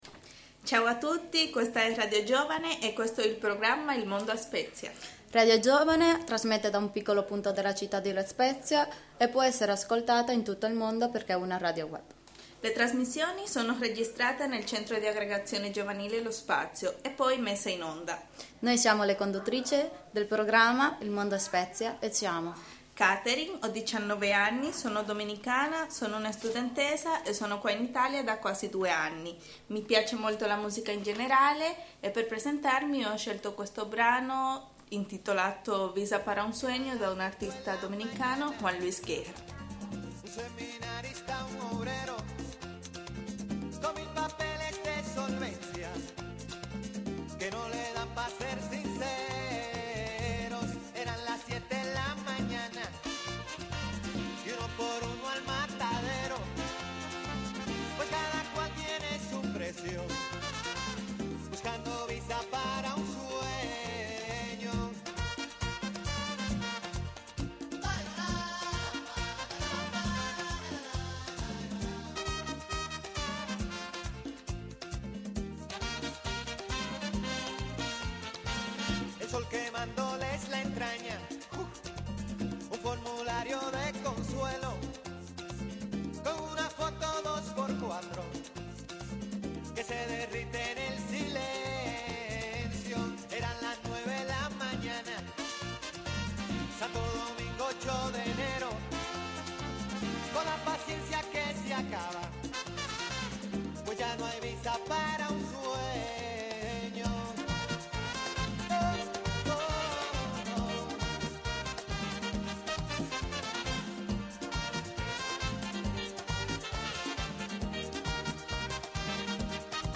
play_circle_filled Il mondo a spezia Radioweb C.A.G. Informagiovani La Spezia Il mondo a Spezia è un programma che racconta la città con gli occhi dei ragazzi che da poco vi si sono stabiliti. Questa è la prima puntata in cui i ragazzi si presentano e presentano il programma